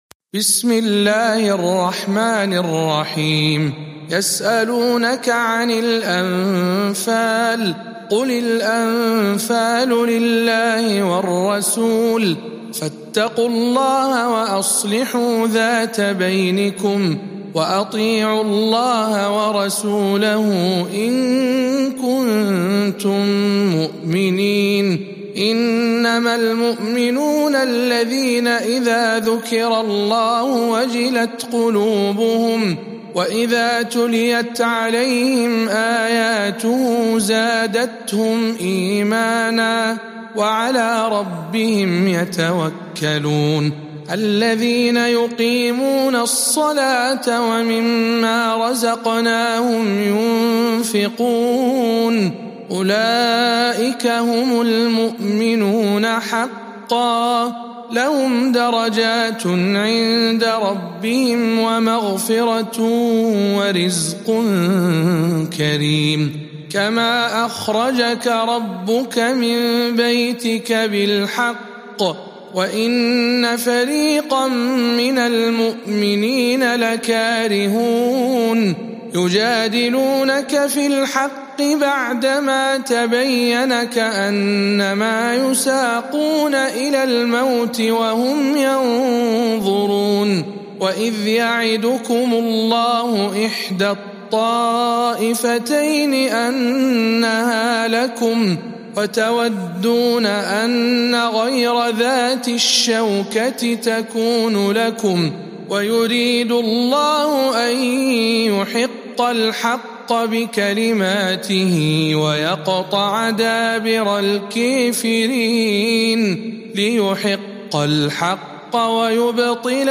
07. سورة الأنفال برواية الدوري عن أبي عمرو